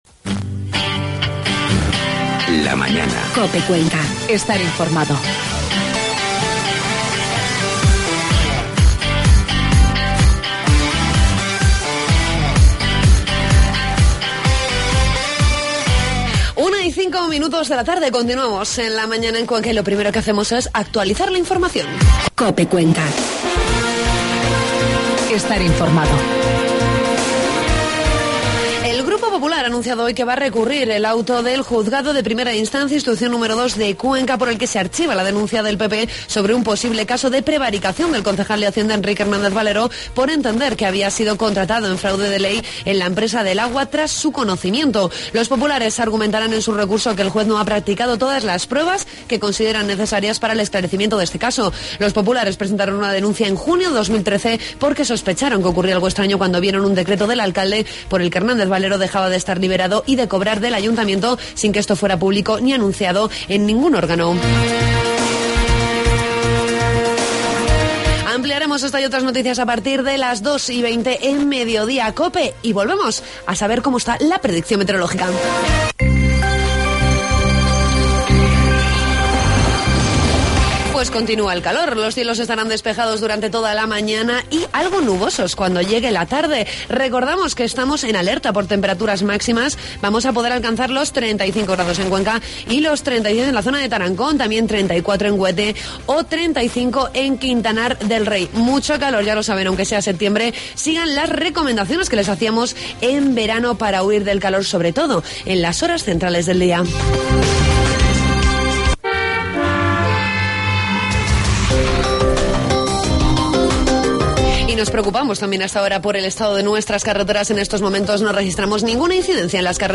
Hacemos balance de la Feria de Artesanía con el presidente de la Diputación, Benjamín Prieto.